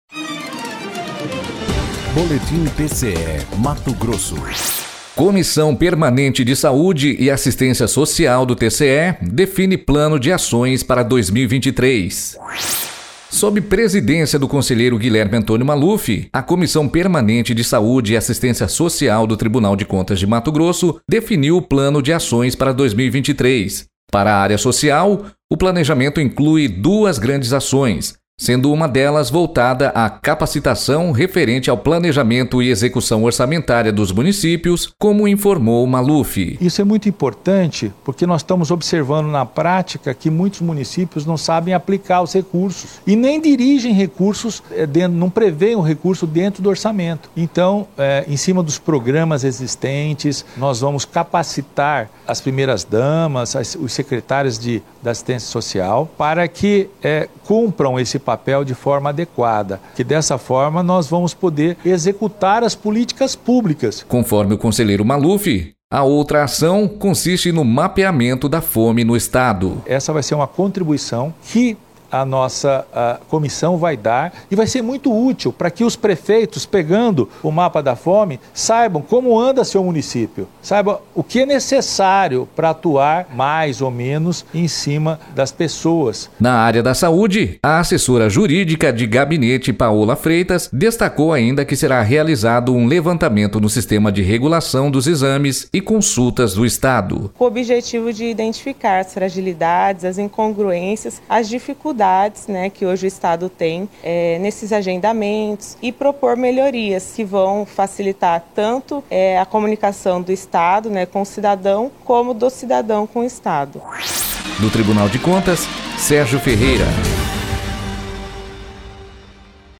Sonora: Guilherme Antonio Maluf – conselheiro presidente da Comissão Permanente de Saúde e Assistência Social do TCE-MT